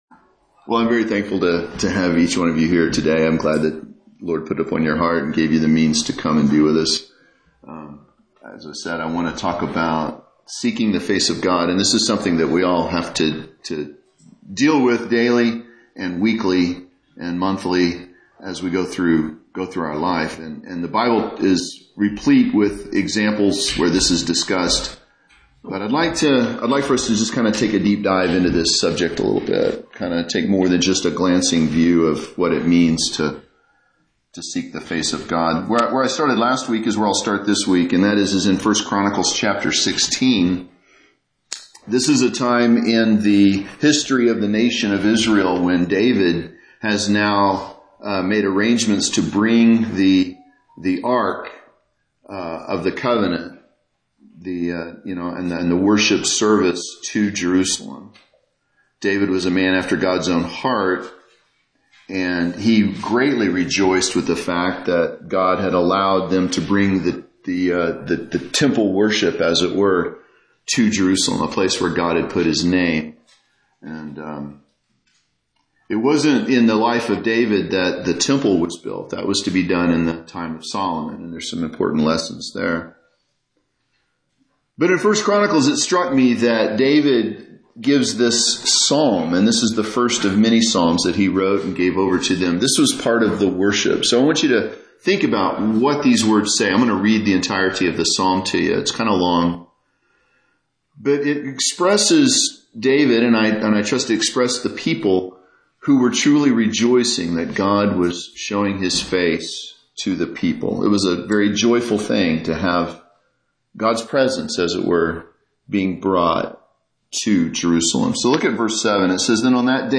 This sermon was recorded at Oxford Primitive Baptist Church Located in Oxford,Kansas